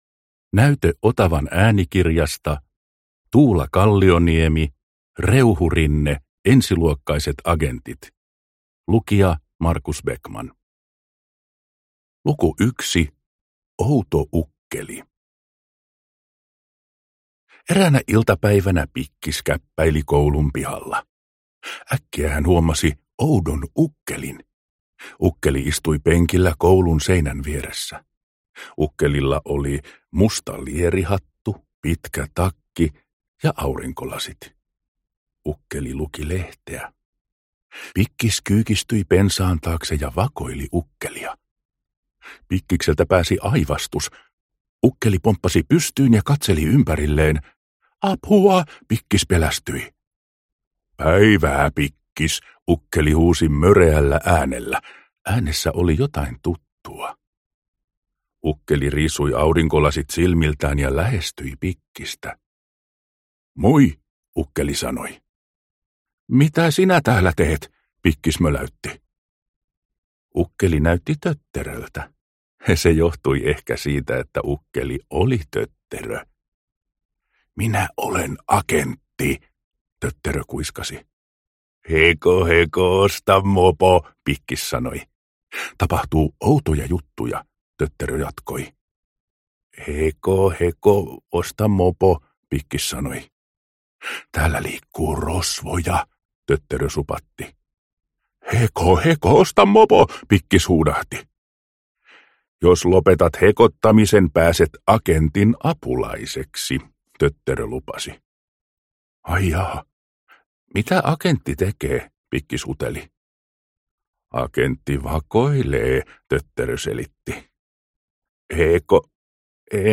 Ensiluokkaiset agentit – Ljudbok – Laddas ner
Hauskoja Reuhurinne-tarinoita äänikirjoina!